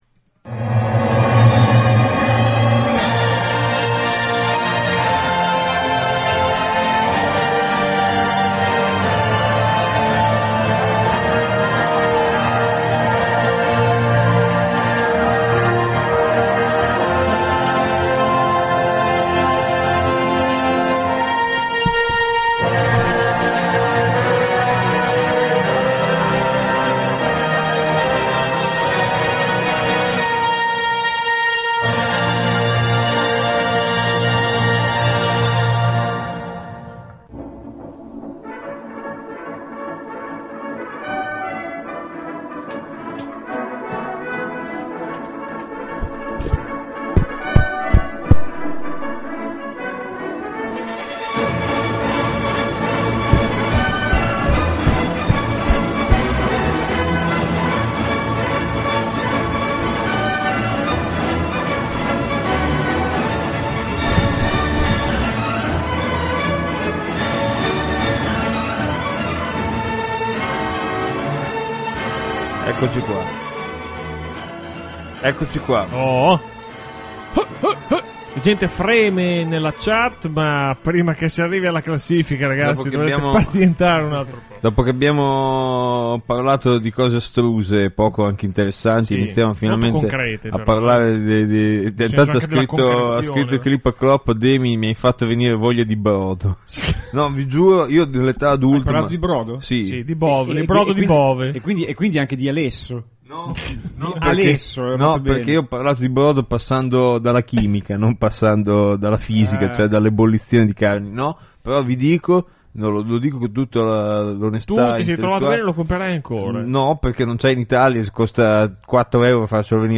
Puntata finale, diluvio di coccorso e premiazione di tutti coloro che meritavano una menzione e/o un premio. Lo studio era affollato dalla quasi totalità dello staff, cosa che non accadeva da anni.
C’è stata polemica, sono volate parole forti, si sono visti scatti arrembanti ma anche sinuose, placide soste meditative. La musica era un pandemonio di istanze, una cornucopia di esperienze auditive.